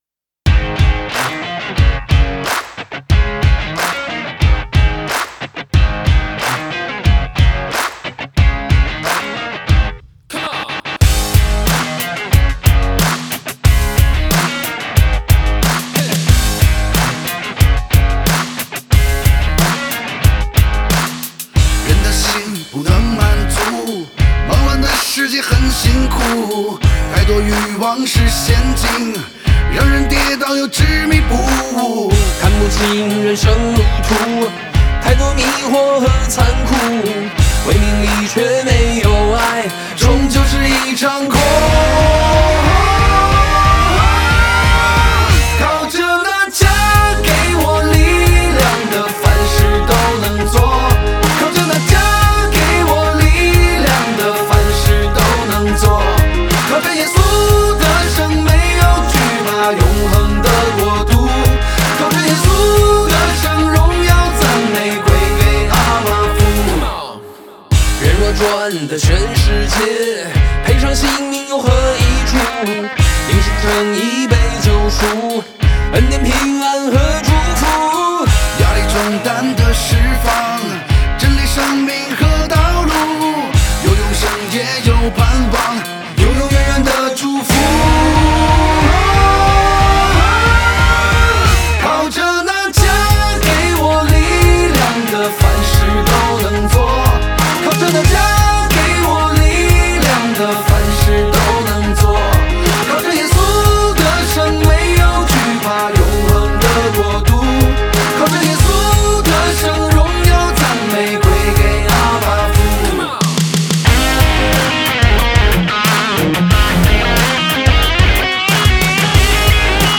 敬拜音乐
HAKA祷告敬拜MP3